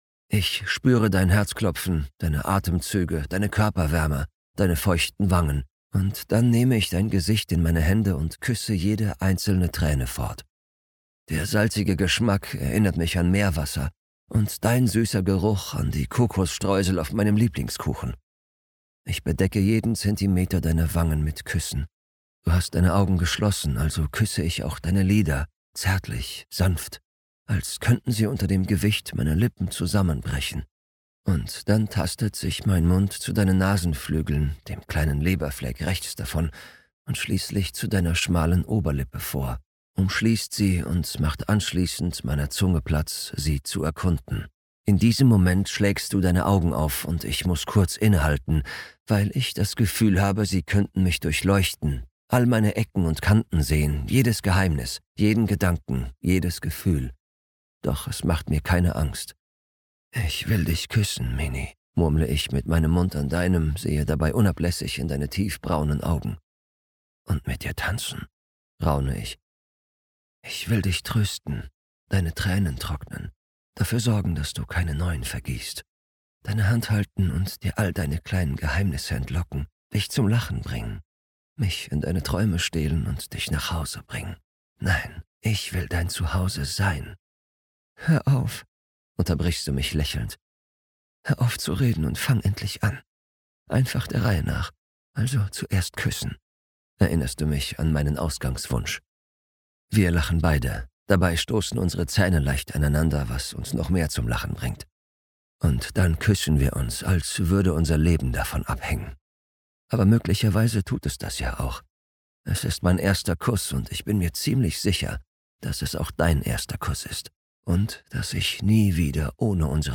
In Kürze erscheint das Re-Release meiner New-Adult-Romance "Love Lyrics - Du bist mein Song" - mit neuem Cover und erstmalig als Hörbuch!
love_lyrics_hoerprobe_davian.mp3